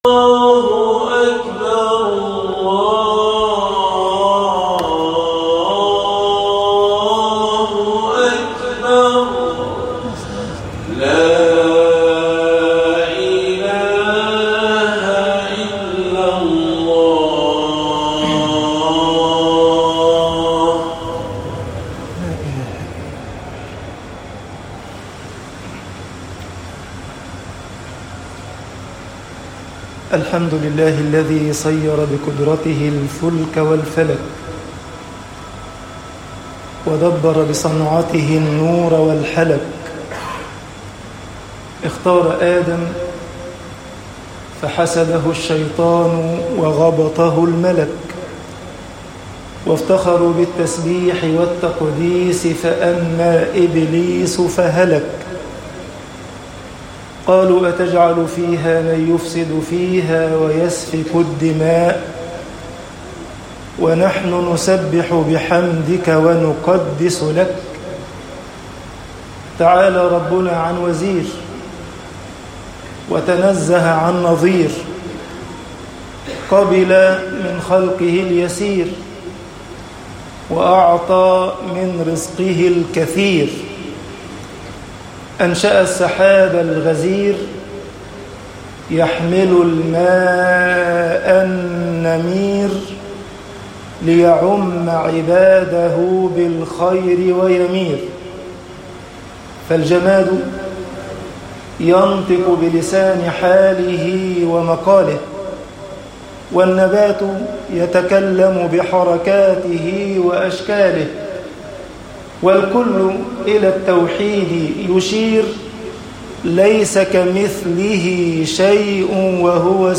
خطب الجمعة - مصر الحُزْن طباعة البريد الإلكتروني التفاصيل كتب بواسطة